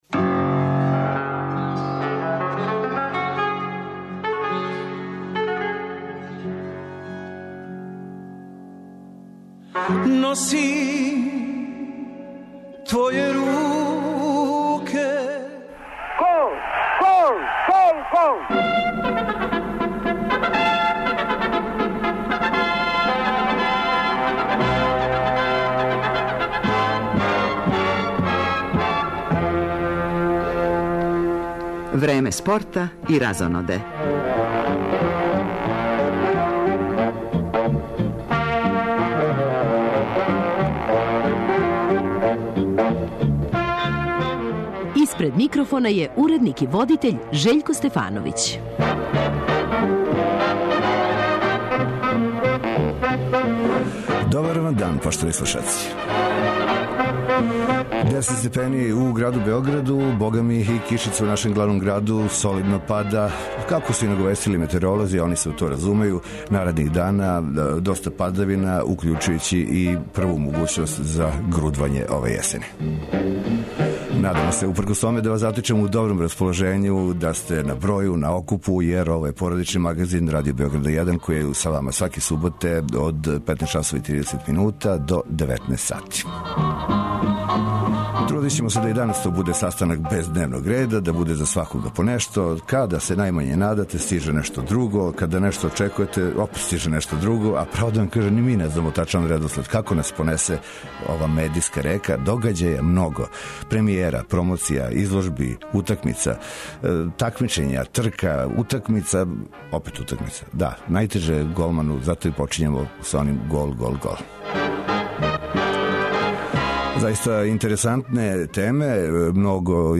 Уз укључења репортера са утакмица Супер лиге Србије у фудбалу, од спорта издвајамо и нови велики успех џудисте Александра Кукоља, мачевалке Романе Царан, као и причу о рукометном клубу Врбас.